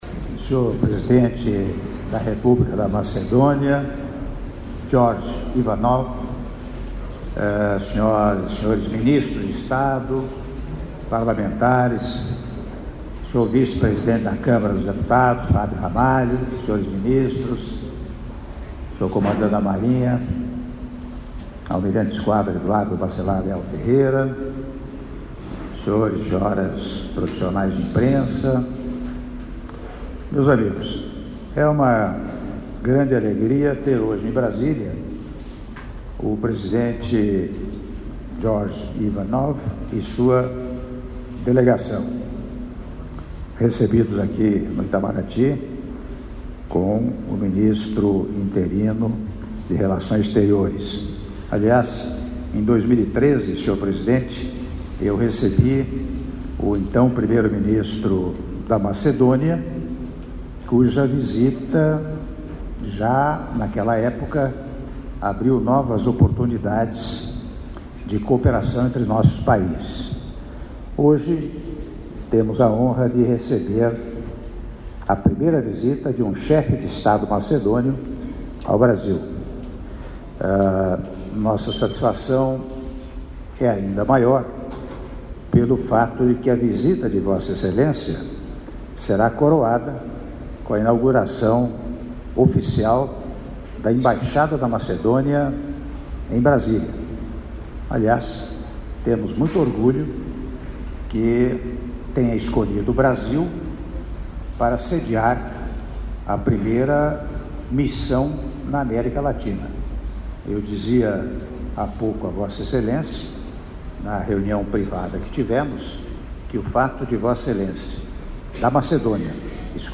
Áudio do brinde do Presidente da República, Michel Temer, durante almoço em homenagem ao senhor Gjorge Ivanov, Presidente da República da Macedônia - (05min13s) - Brasília/DF